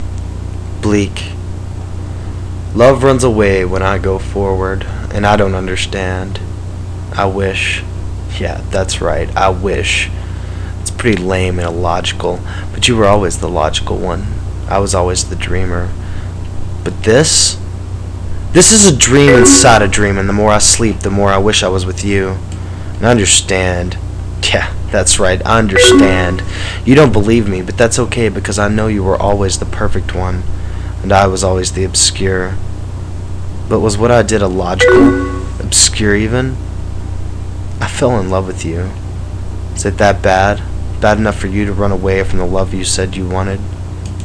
Main Index Table of Contents Hear me read Bleak